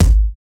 Kick 5 (With You, Friends).wav